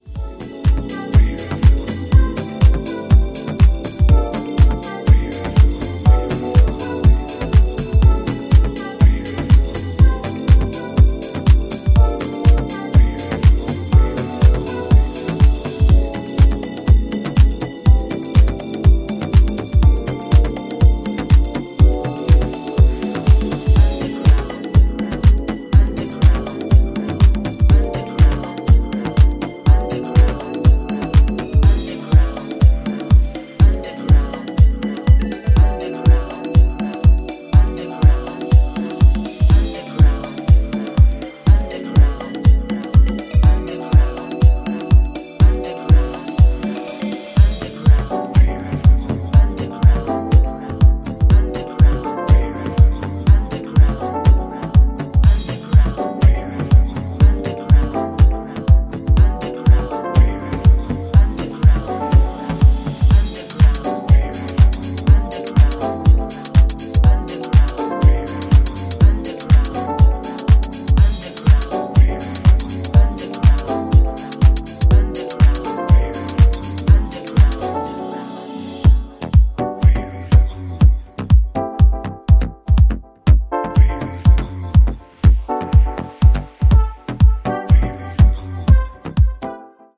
ドリーミーな90s イタリアン・ハウスに倣うディープ・ハウス群を展開しており、浮遊感漂うコードが浸透していく